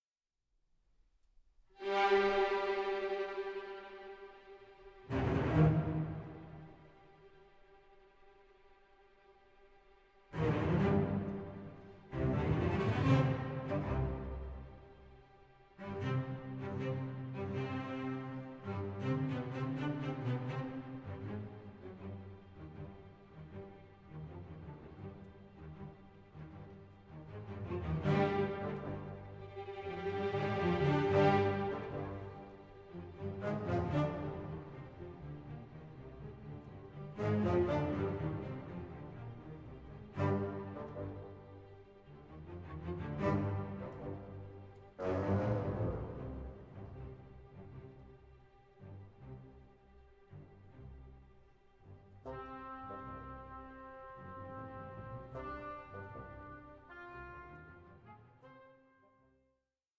Symphony No. 2 in C Minor